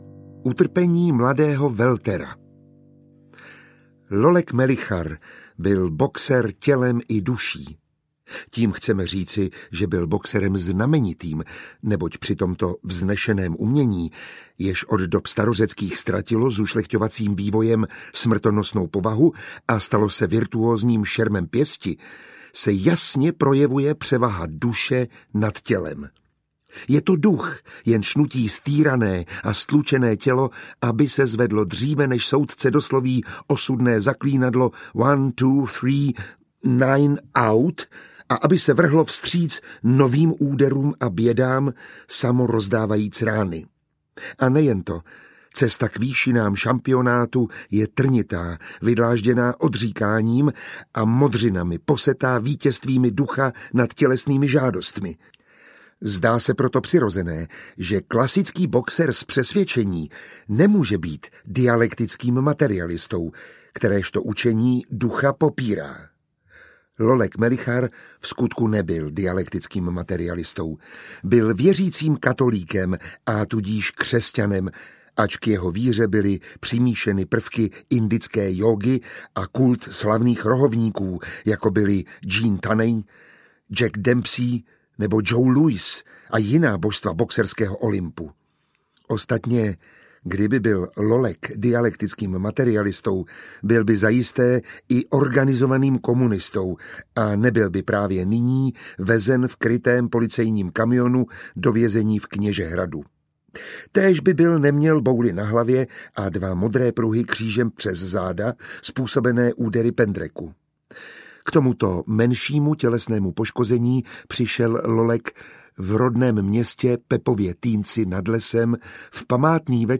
Na úsvitě nové doby audiokniha
Ukázka z knihy
• InterpretMiroslav Táborský